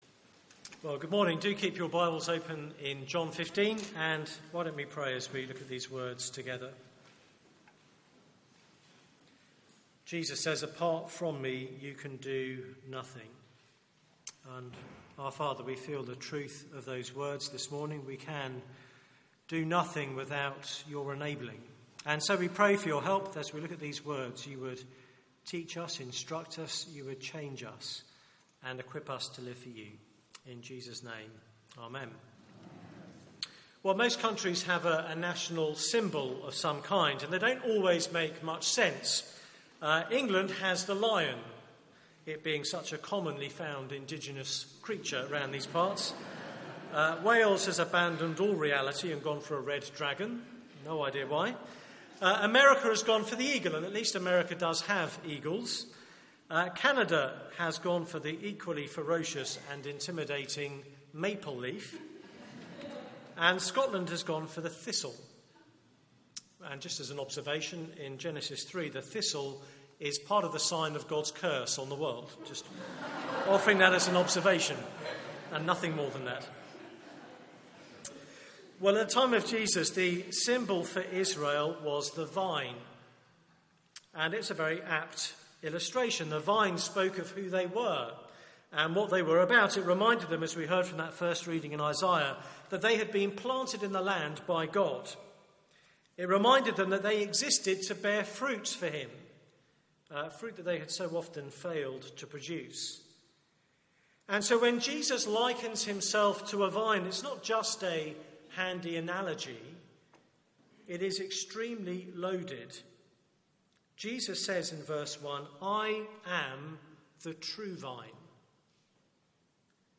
Media for 9:15am Service on Sun 12th Jun 2016
Series: The gathering storm Theme: Fragile but fruitful Sermon